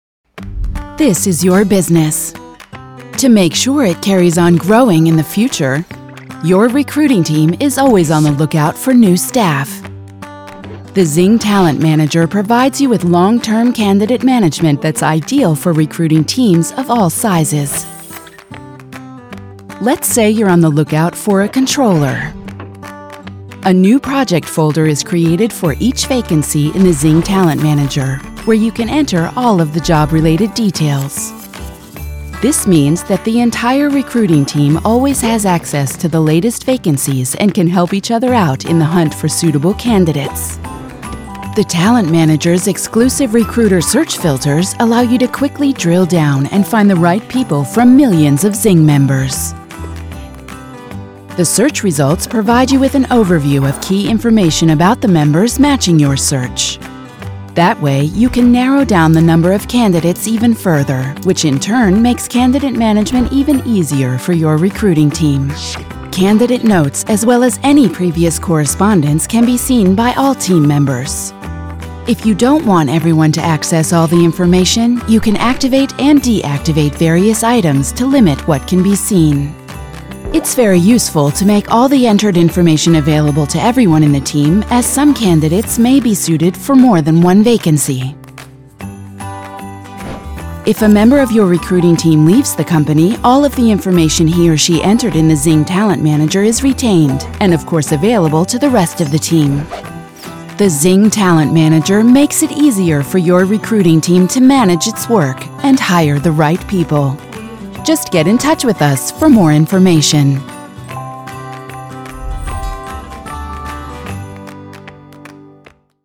American Voice-Over Artist Native Sprecherin US Englisch
Sprechprobe: Industrie (Muttersprache):